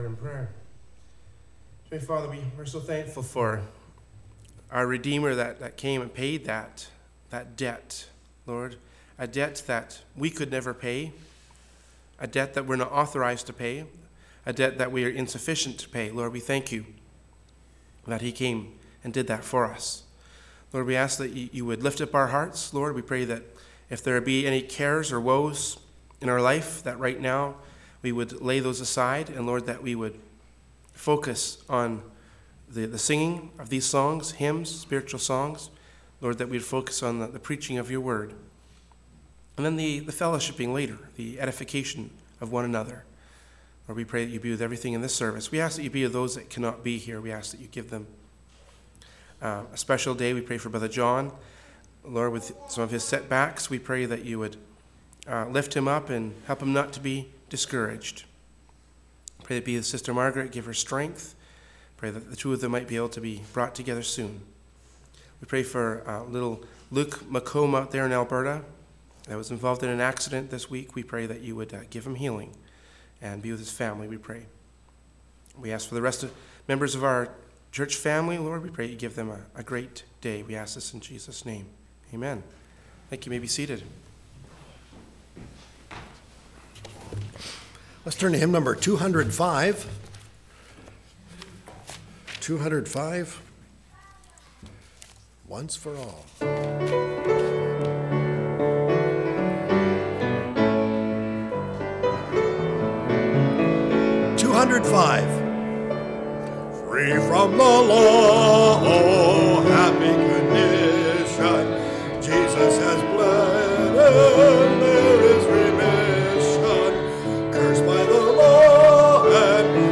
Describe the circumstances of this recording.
“Remeber Lots Wife” from Sunday Morning Worship Service by Berean Baptist Church.